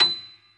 55p-pno39-B6.wav